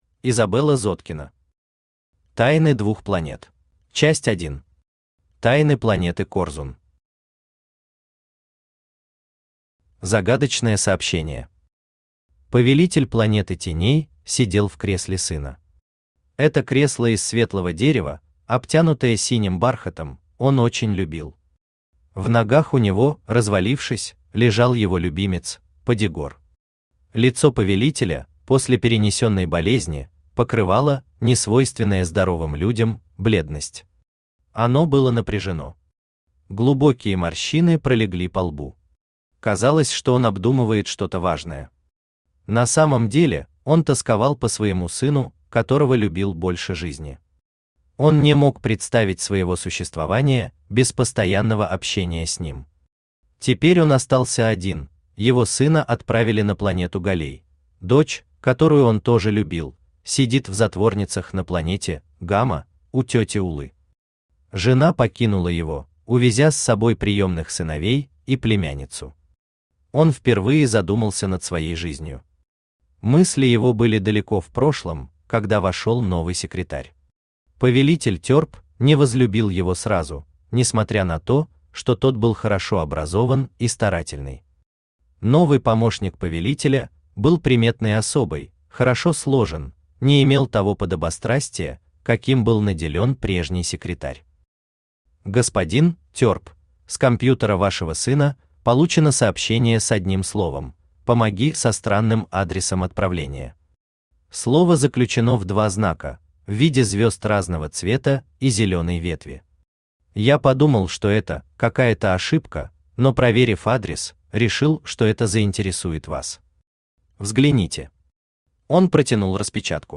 Аудиокнига Тайны двух планет | Библиотека аудиокниг
Aудиокнига Тайны двух планет Автор Изабелла Зоткина Читает аудиокнигу Авточтец ЛитРес.